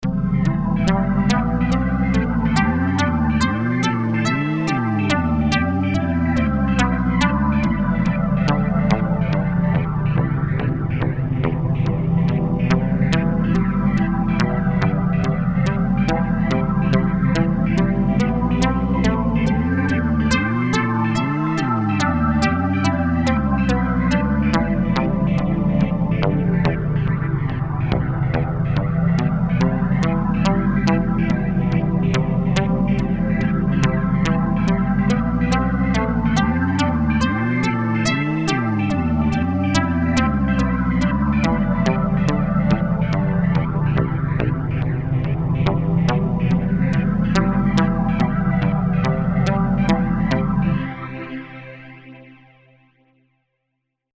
For reference, this is one of the MIDI tests, which uses a simple Piano part done in Notion 3 and exported as a MIDI file to drive Reason 5, where the musical phrase is repeated three times . . .
Reason 5 Synthesizers Set to Play the MIDI Notes Imported from Notion 3 ~ Reason 5
This is the MP3 created in iTunes from the AIFF file exported from Reason 5 . . .